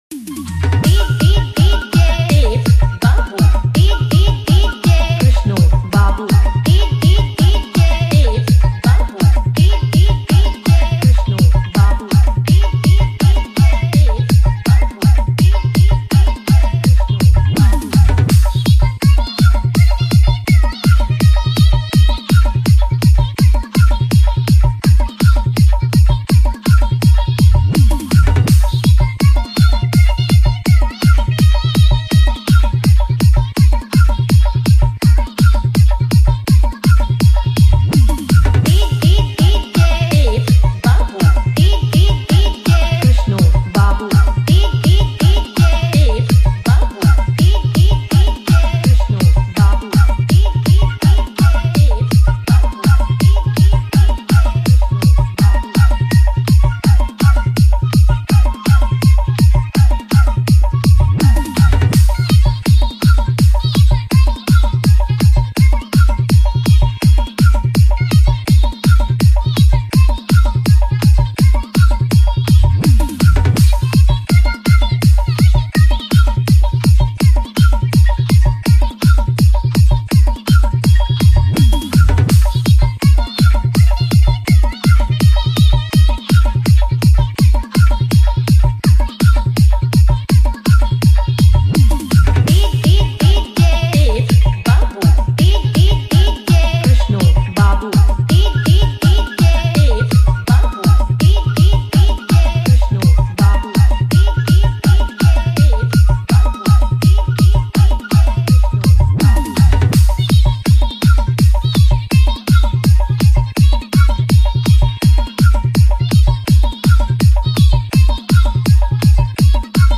New Nagpuri Dj Song 2025